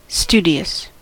studious: Wikimedia Commons US English Pronunciations
En-us-studious.WAV